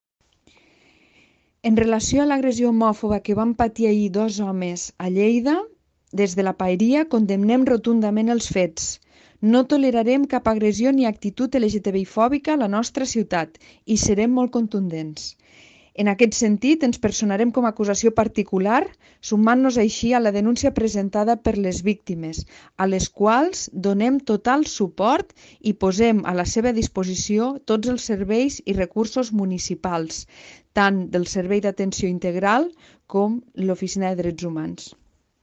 La regidora d’Educació, Cooperació, Drets Civils i Feminisme, Sandra Castro, ha assegurat que fets com aquests són intolerables i que la Paeria no permetrà que l’LGTBIfòbia sigui present a Lleida. Tall de veu de Sandra Castro Compartir Facebook Twitter Whatsapp Descarregar ODT Imprimir Tornar a notícies Fitxers relacionats Tall de veu de Sandra Castro sobre el rebuig a una agressió homòfoba a Lleida (409.2 KB) T'ha estat útil aquesta pàgina?